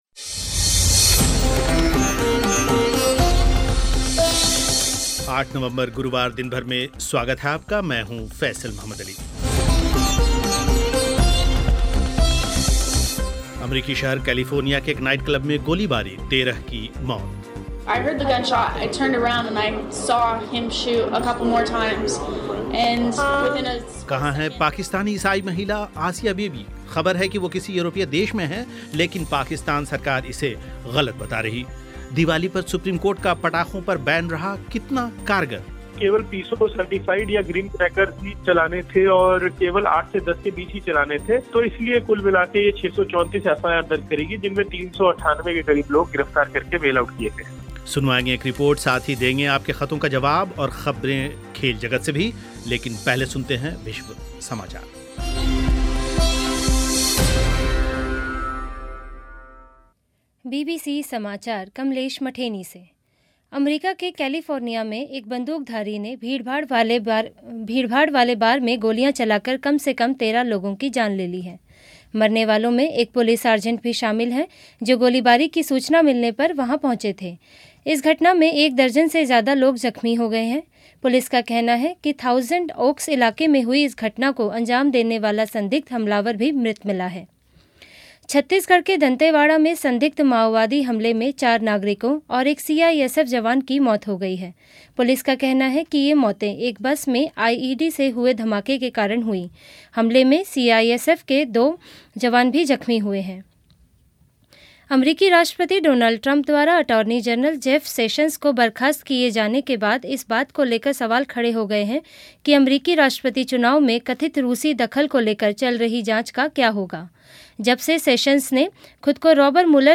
अमरीकी शहर कैलिफोर्निया के एक नाइट क्लब में गोलीबारी, 13 की मौत, सुनें एक रिपोर्ट